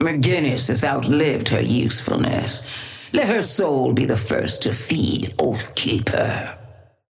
Patron_female_ally_ghost_oathkeeper_5a_vs_mcg_start_01.mp3